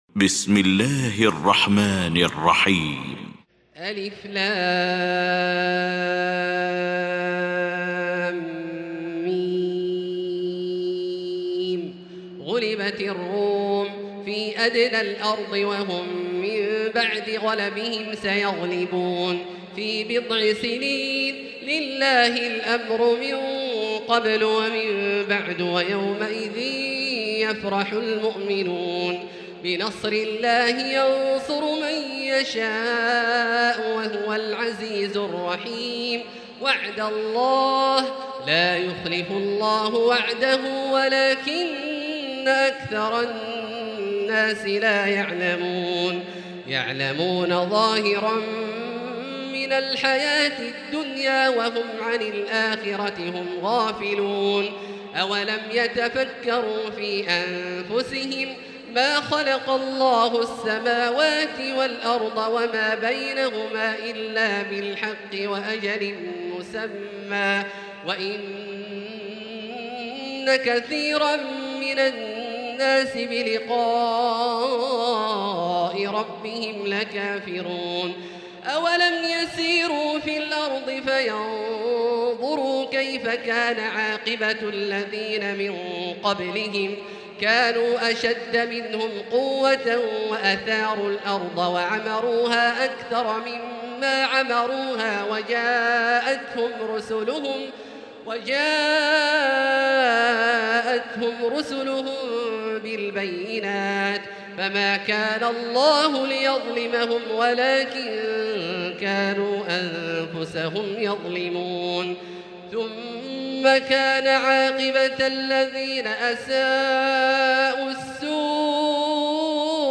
المكان: المسجد الحرام الشيخ: فضيلة الشيخ عبدالله الجهني فضيلة الشيخ عبدالله الجهني فضيلة الشيخ ياسر الدوسري الروم